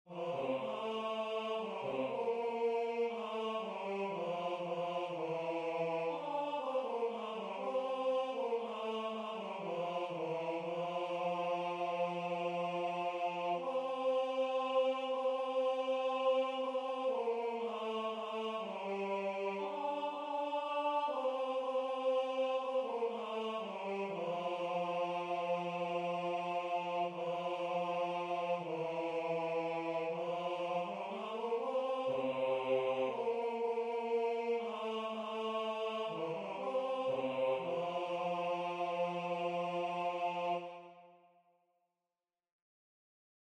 heren